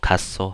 -so
kasso blunt